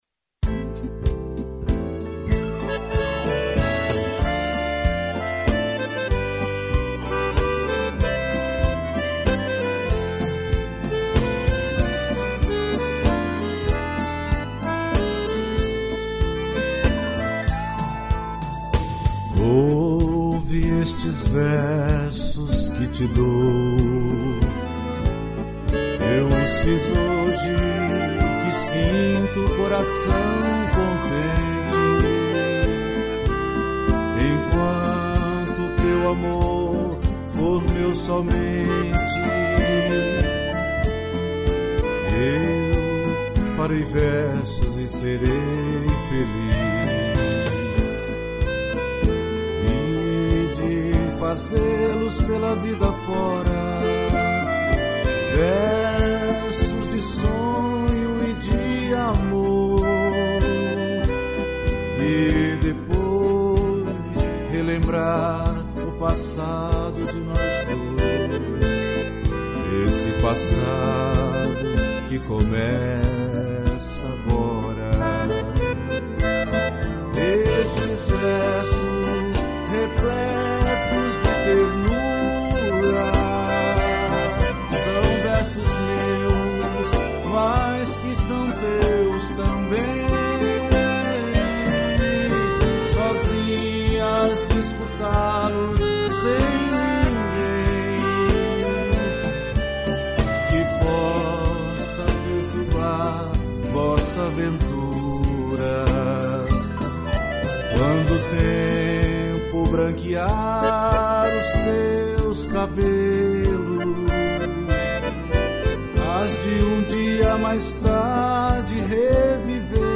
acordeão